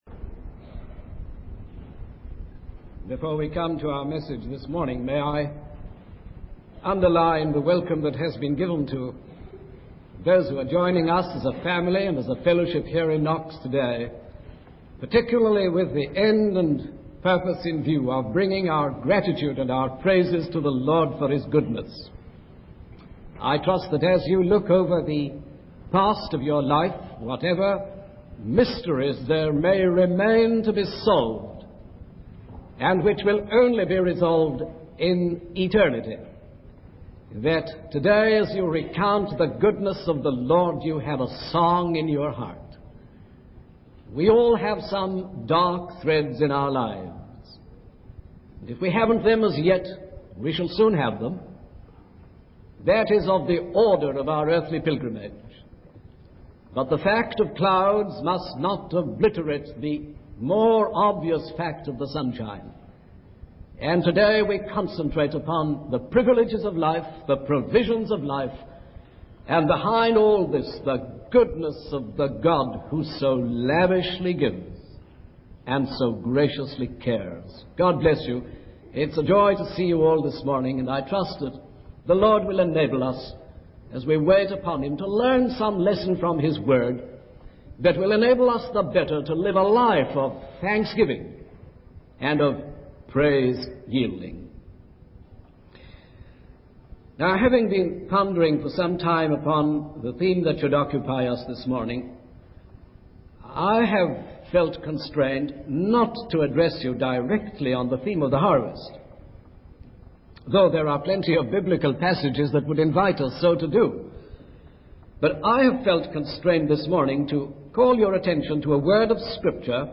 In this sermon, the speaker emphasizes the importance of recognizing and acknowledging God's goodness in our lives.